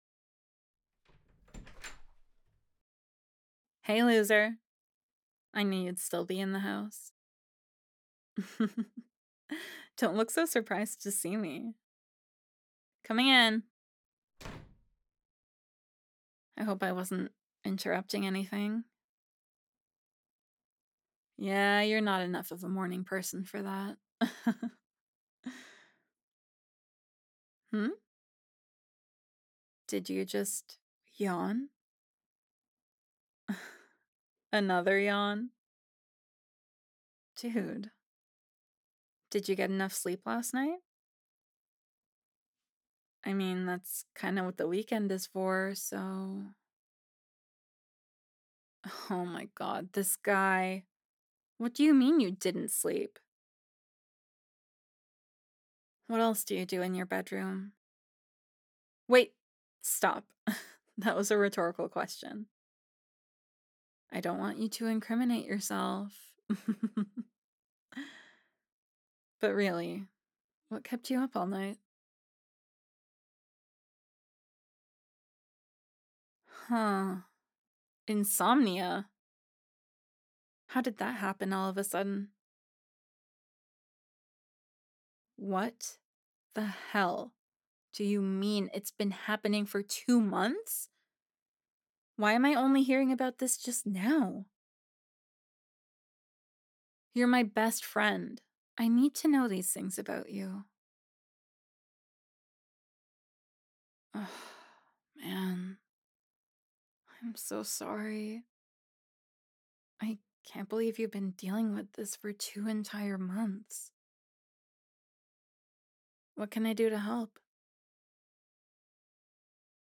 Tags: [Relaxing] [Insomnia Comfort] [Soft] [Audible Headpats] [Just Relax] [Lap Pillow] [Falling Asleep With Your Friend] [Sweet] [Platonic Jabs/Teasing] [Friends To ???] [Open Ending]